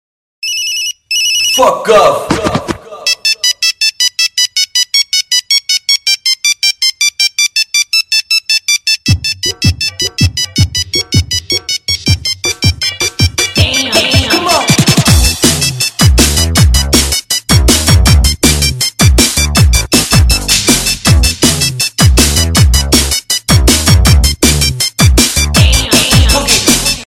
klasicke zvonenie skombinovane s rapom 0:26